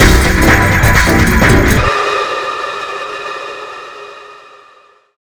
Salsa Martian 2 123-E.wav